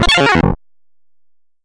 游戏音效 " FX464 - 声音 - 淘声网 - 免费音效素材资源|视频游戏配乐下载